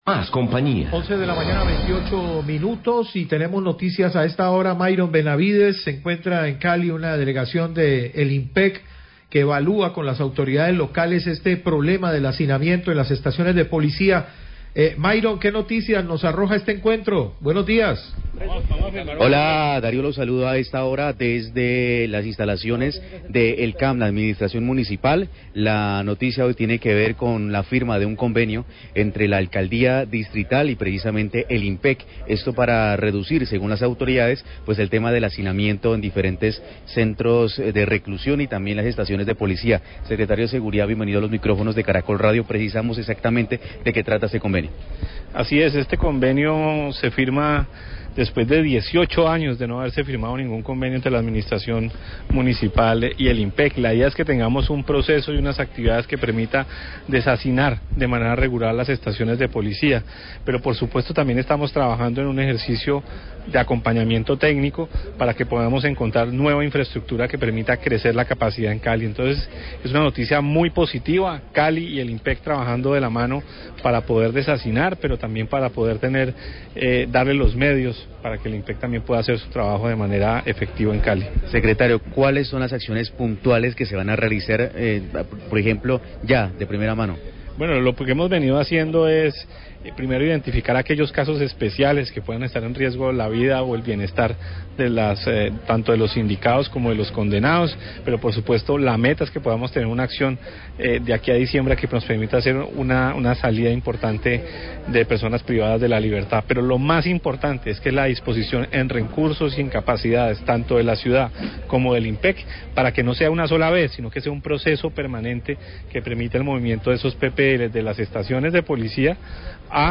Radio
Convenio entre alcaldia y el IMPEC para tratar el tema de hacinamiento en las estaciones de policía de la ciudad, el Secretario de Seguridad, Jairo García, y el Director Regional del IMPEC, Guillermo Gonsalez, hablan al respecto.